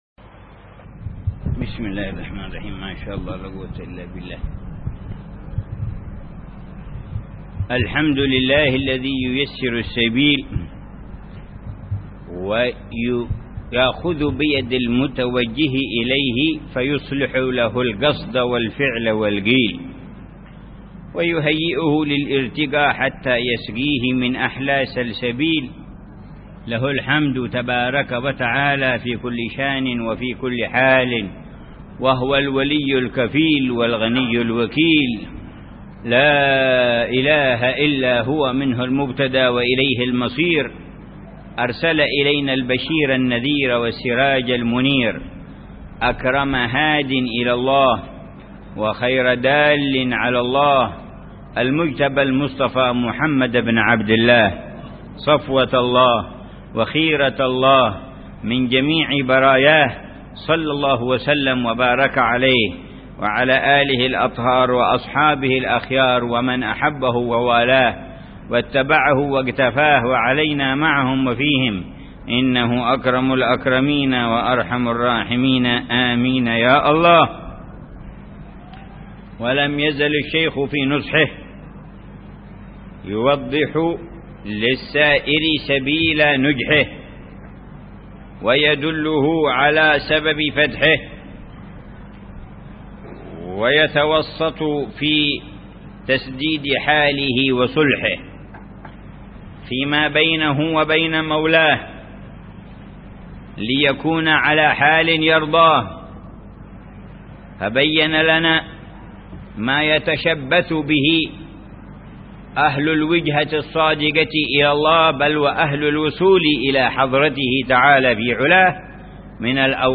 قراءة بتأمل وشرح لمعاني كتاب قوت القلوب للشيخ: أبي طالب المكي ضمن دروس الدورة التعليمية السادسة عشرة بدار المصطفى 1431هجرية.